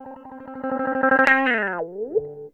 Track 13 - Clean Guitar Wah 02.wav